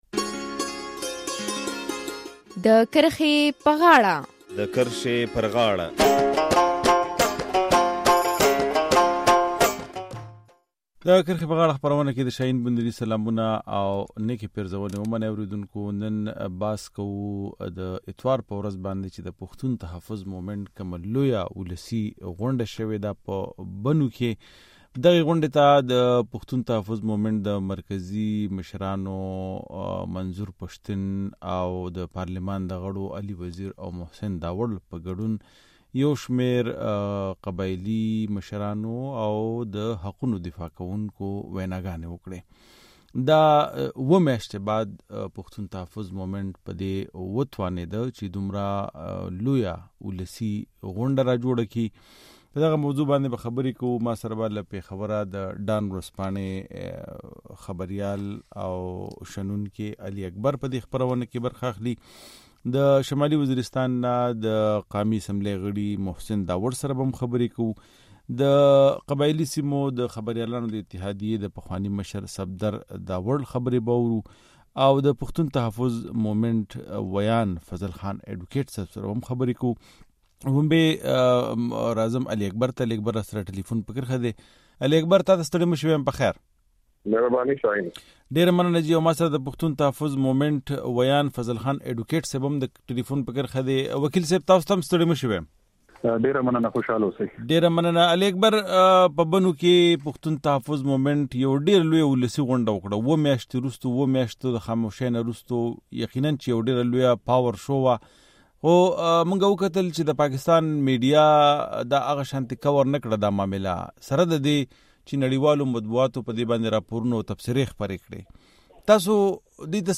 د جنورۍ پر۱۲مه پښتون ژغورنې غورځنګ په بنو کې یوه لویه ولسي غونډه وکړه او یو ځل بیا يې د لادرکه خلکو عدالتونو ته وړاندې کولو، سیمه د ماینونو څخه پاکولو او د روغې جوړې د یو کمیشن جوړولو غوښتنه وکړه. ځېنو نړیوالو مطبوعاتو په دغه اړه راپورونه خپاره کړه خو د پاکستان میډیا په دغه اړه خاموشه پاتې شوه پر دې د کرښې پر غاړه کې بحث کوو.